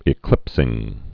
(ĭ-klĭpsĭng)